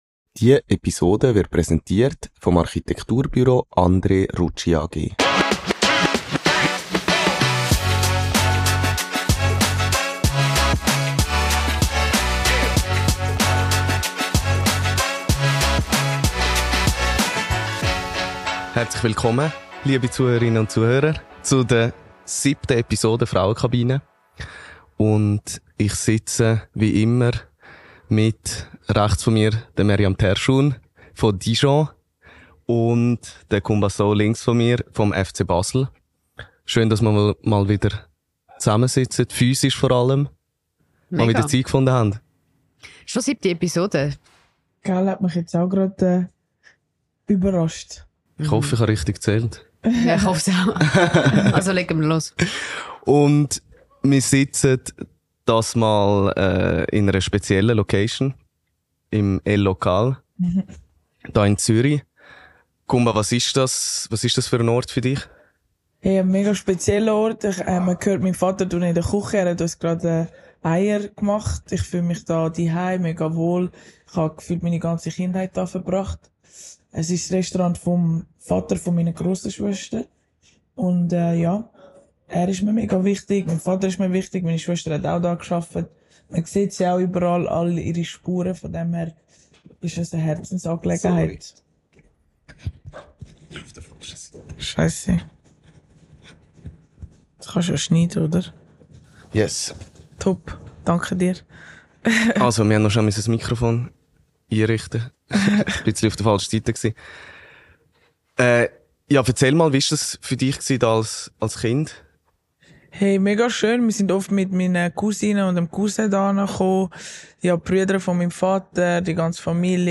Presenting Partner: Architekturbüro André Rutschi AG Location: El Lokal, Zürich Mehr